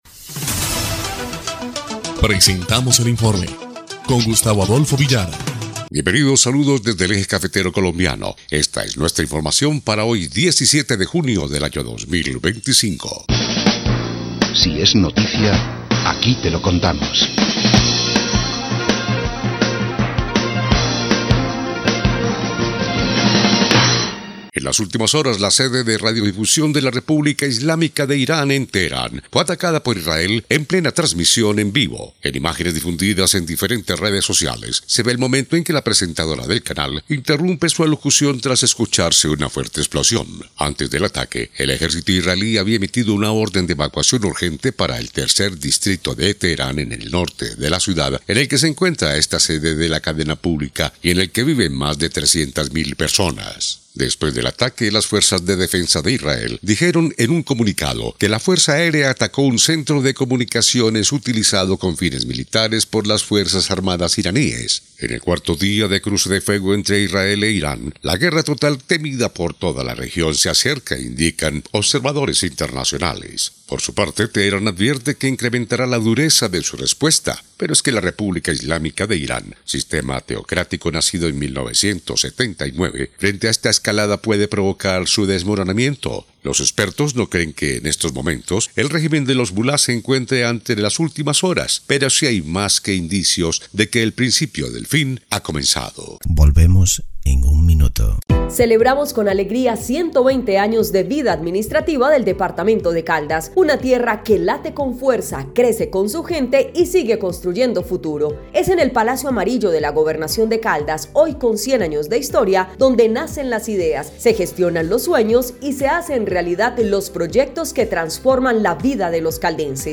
EL INFORME 1° Clip de Noticias del 17 de junio de 2025